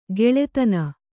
♪ geḷetana